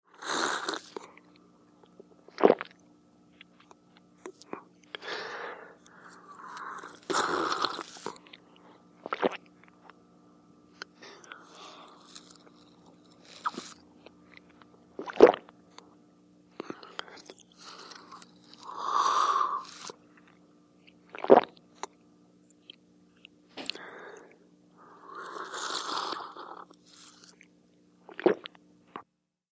DINING - KITCHENS & EATING DRINKING: INT: Swallows & sipping.